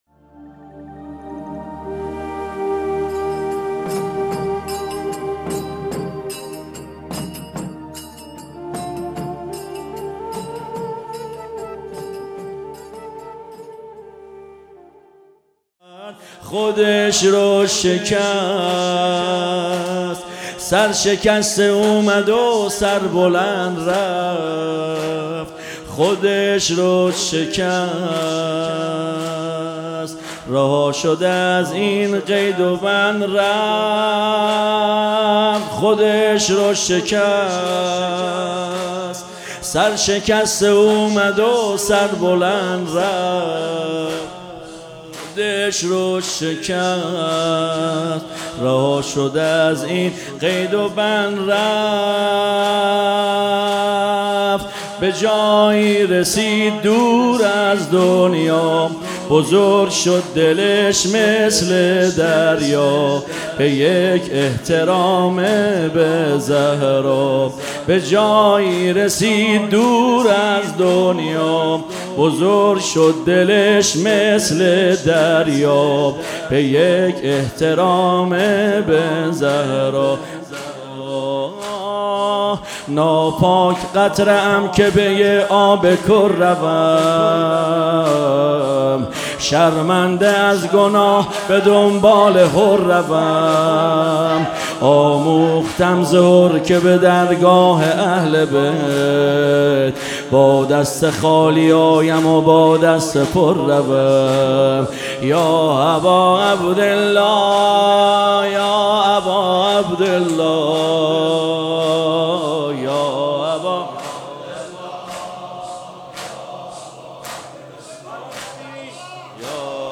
زمینه | خودش رو شکست، سر شکسته اومدُ سربلند رفت
مداحی
در محرم الحرام سال 1399